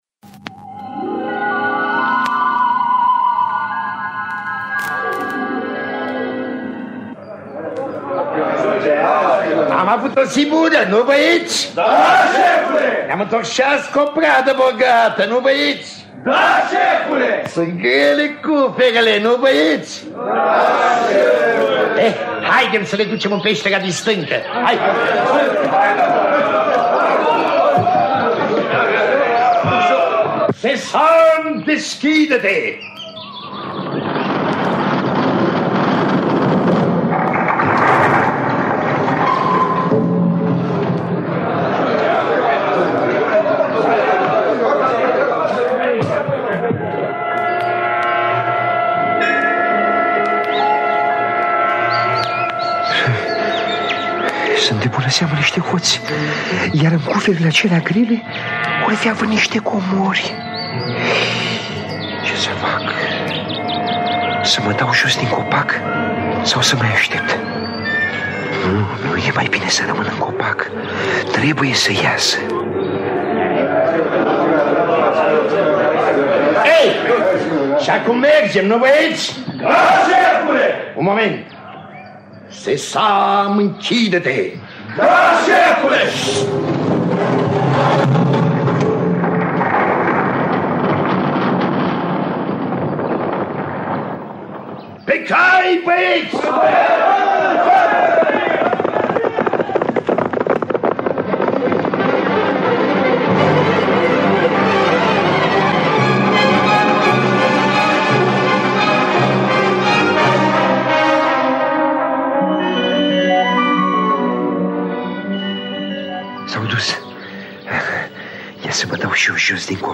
Dramatizarea radiofonică de Marin Traian.